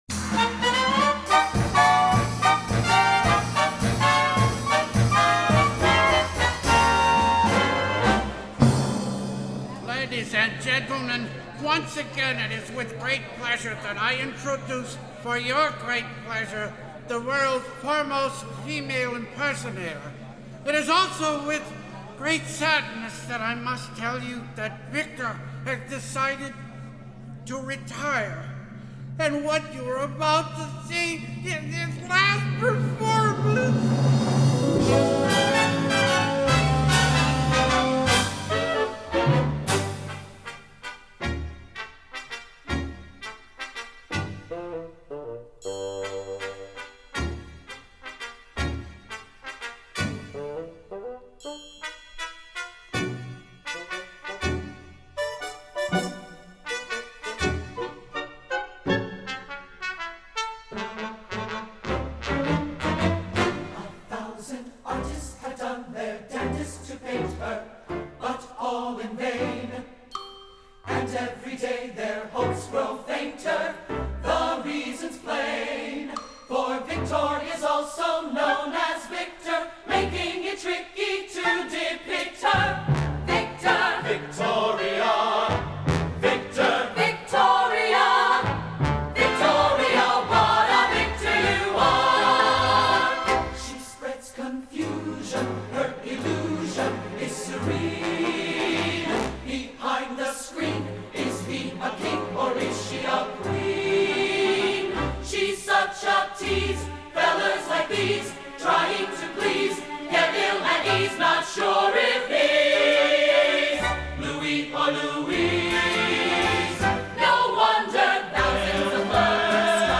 Genre: Musical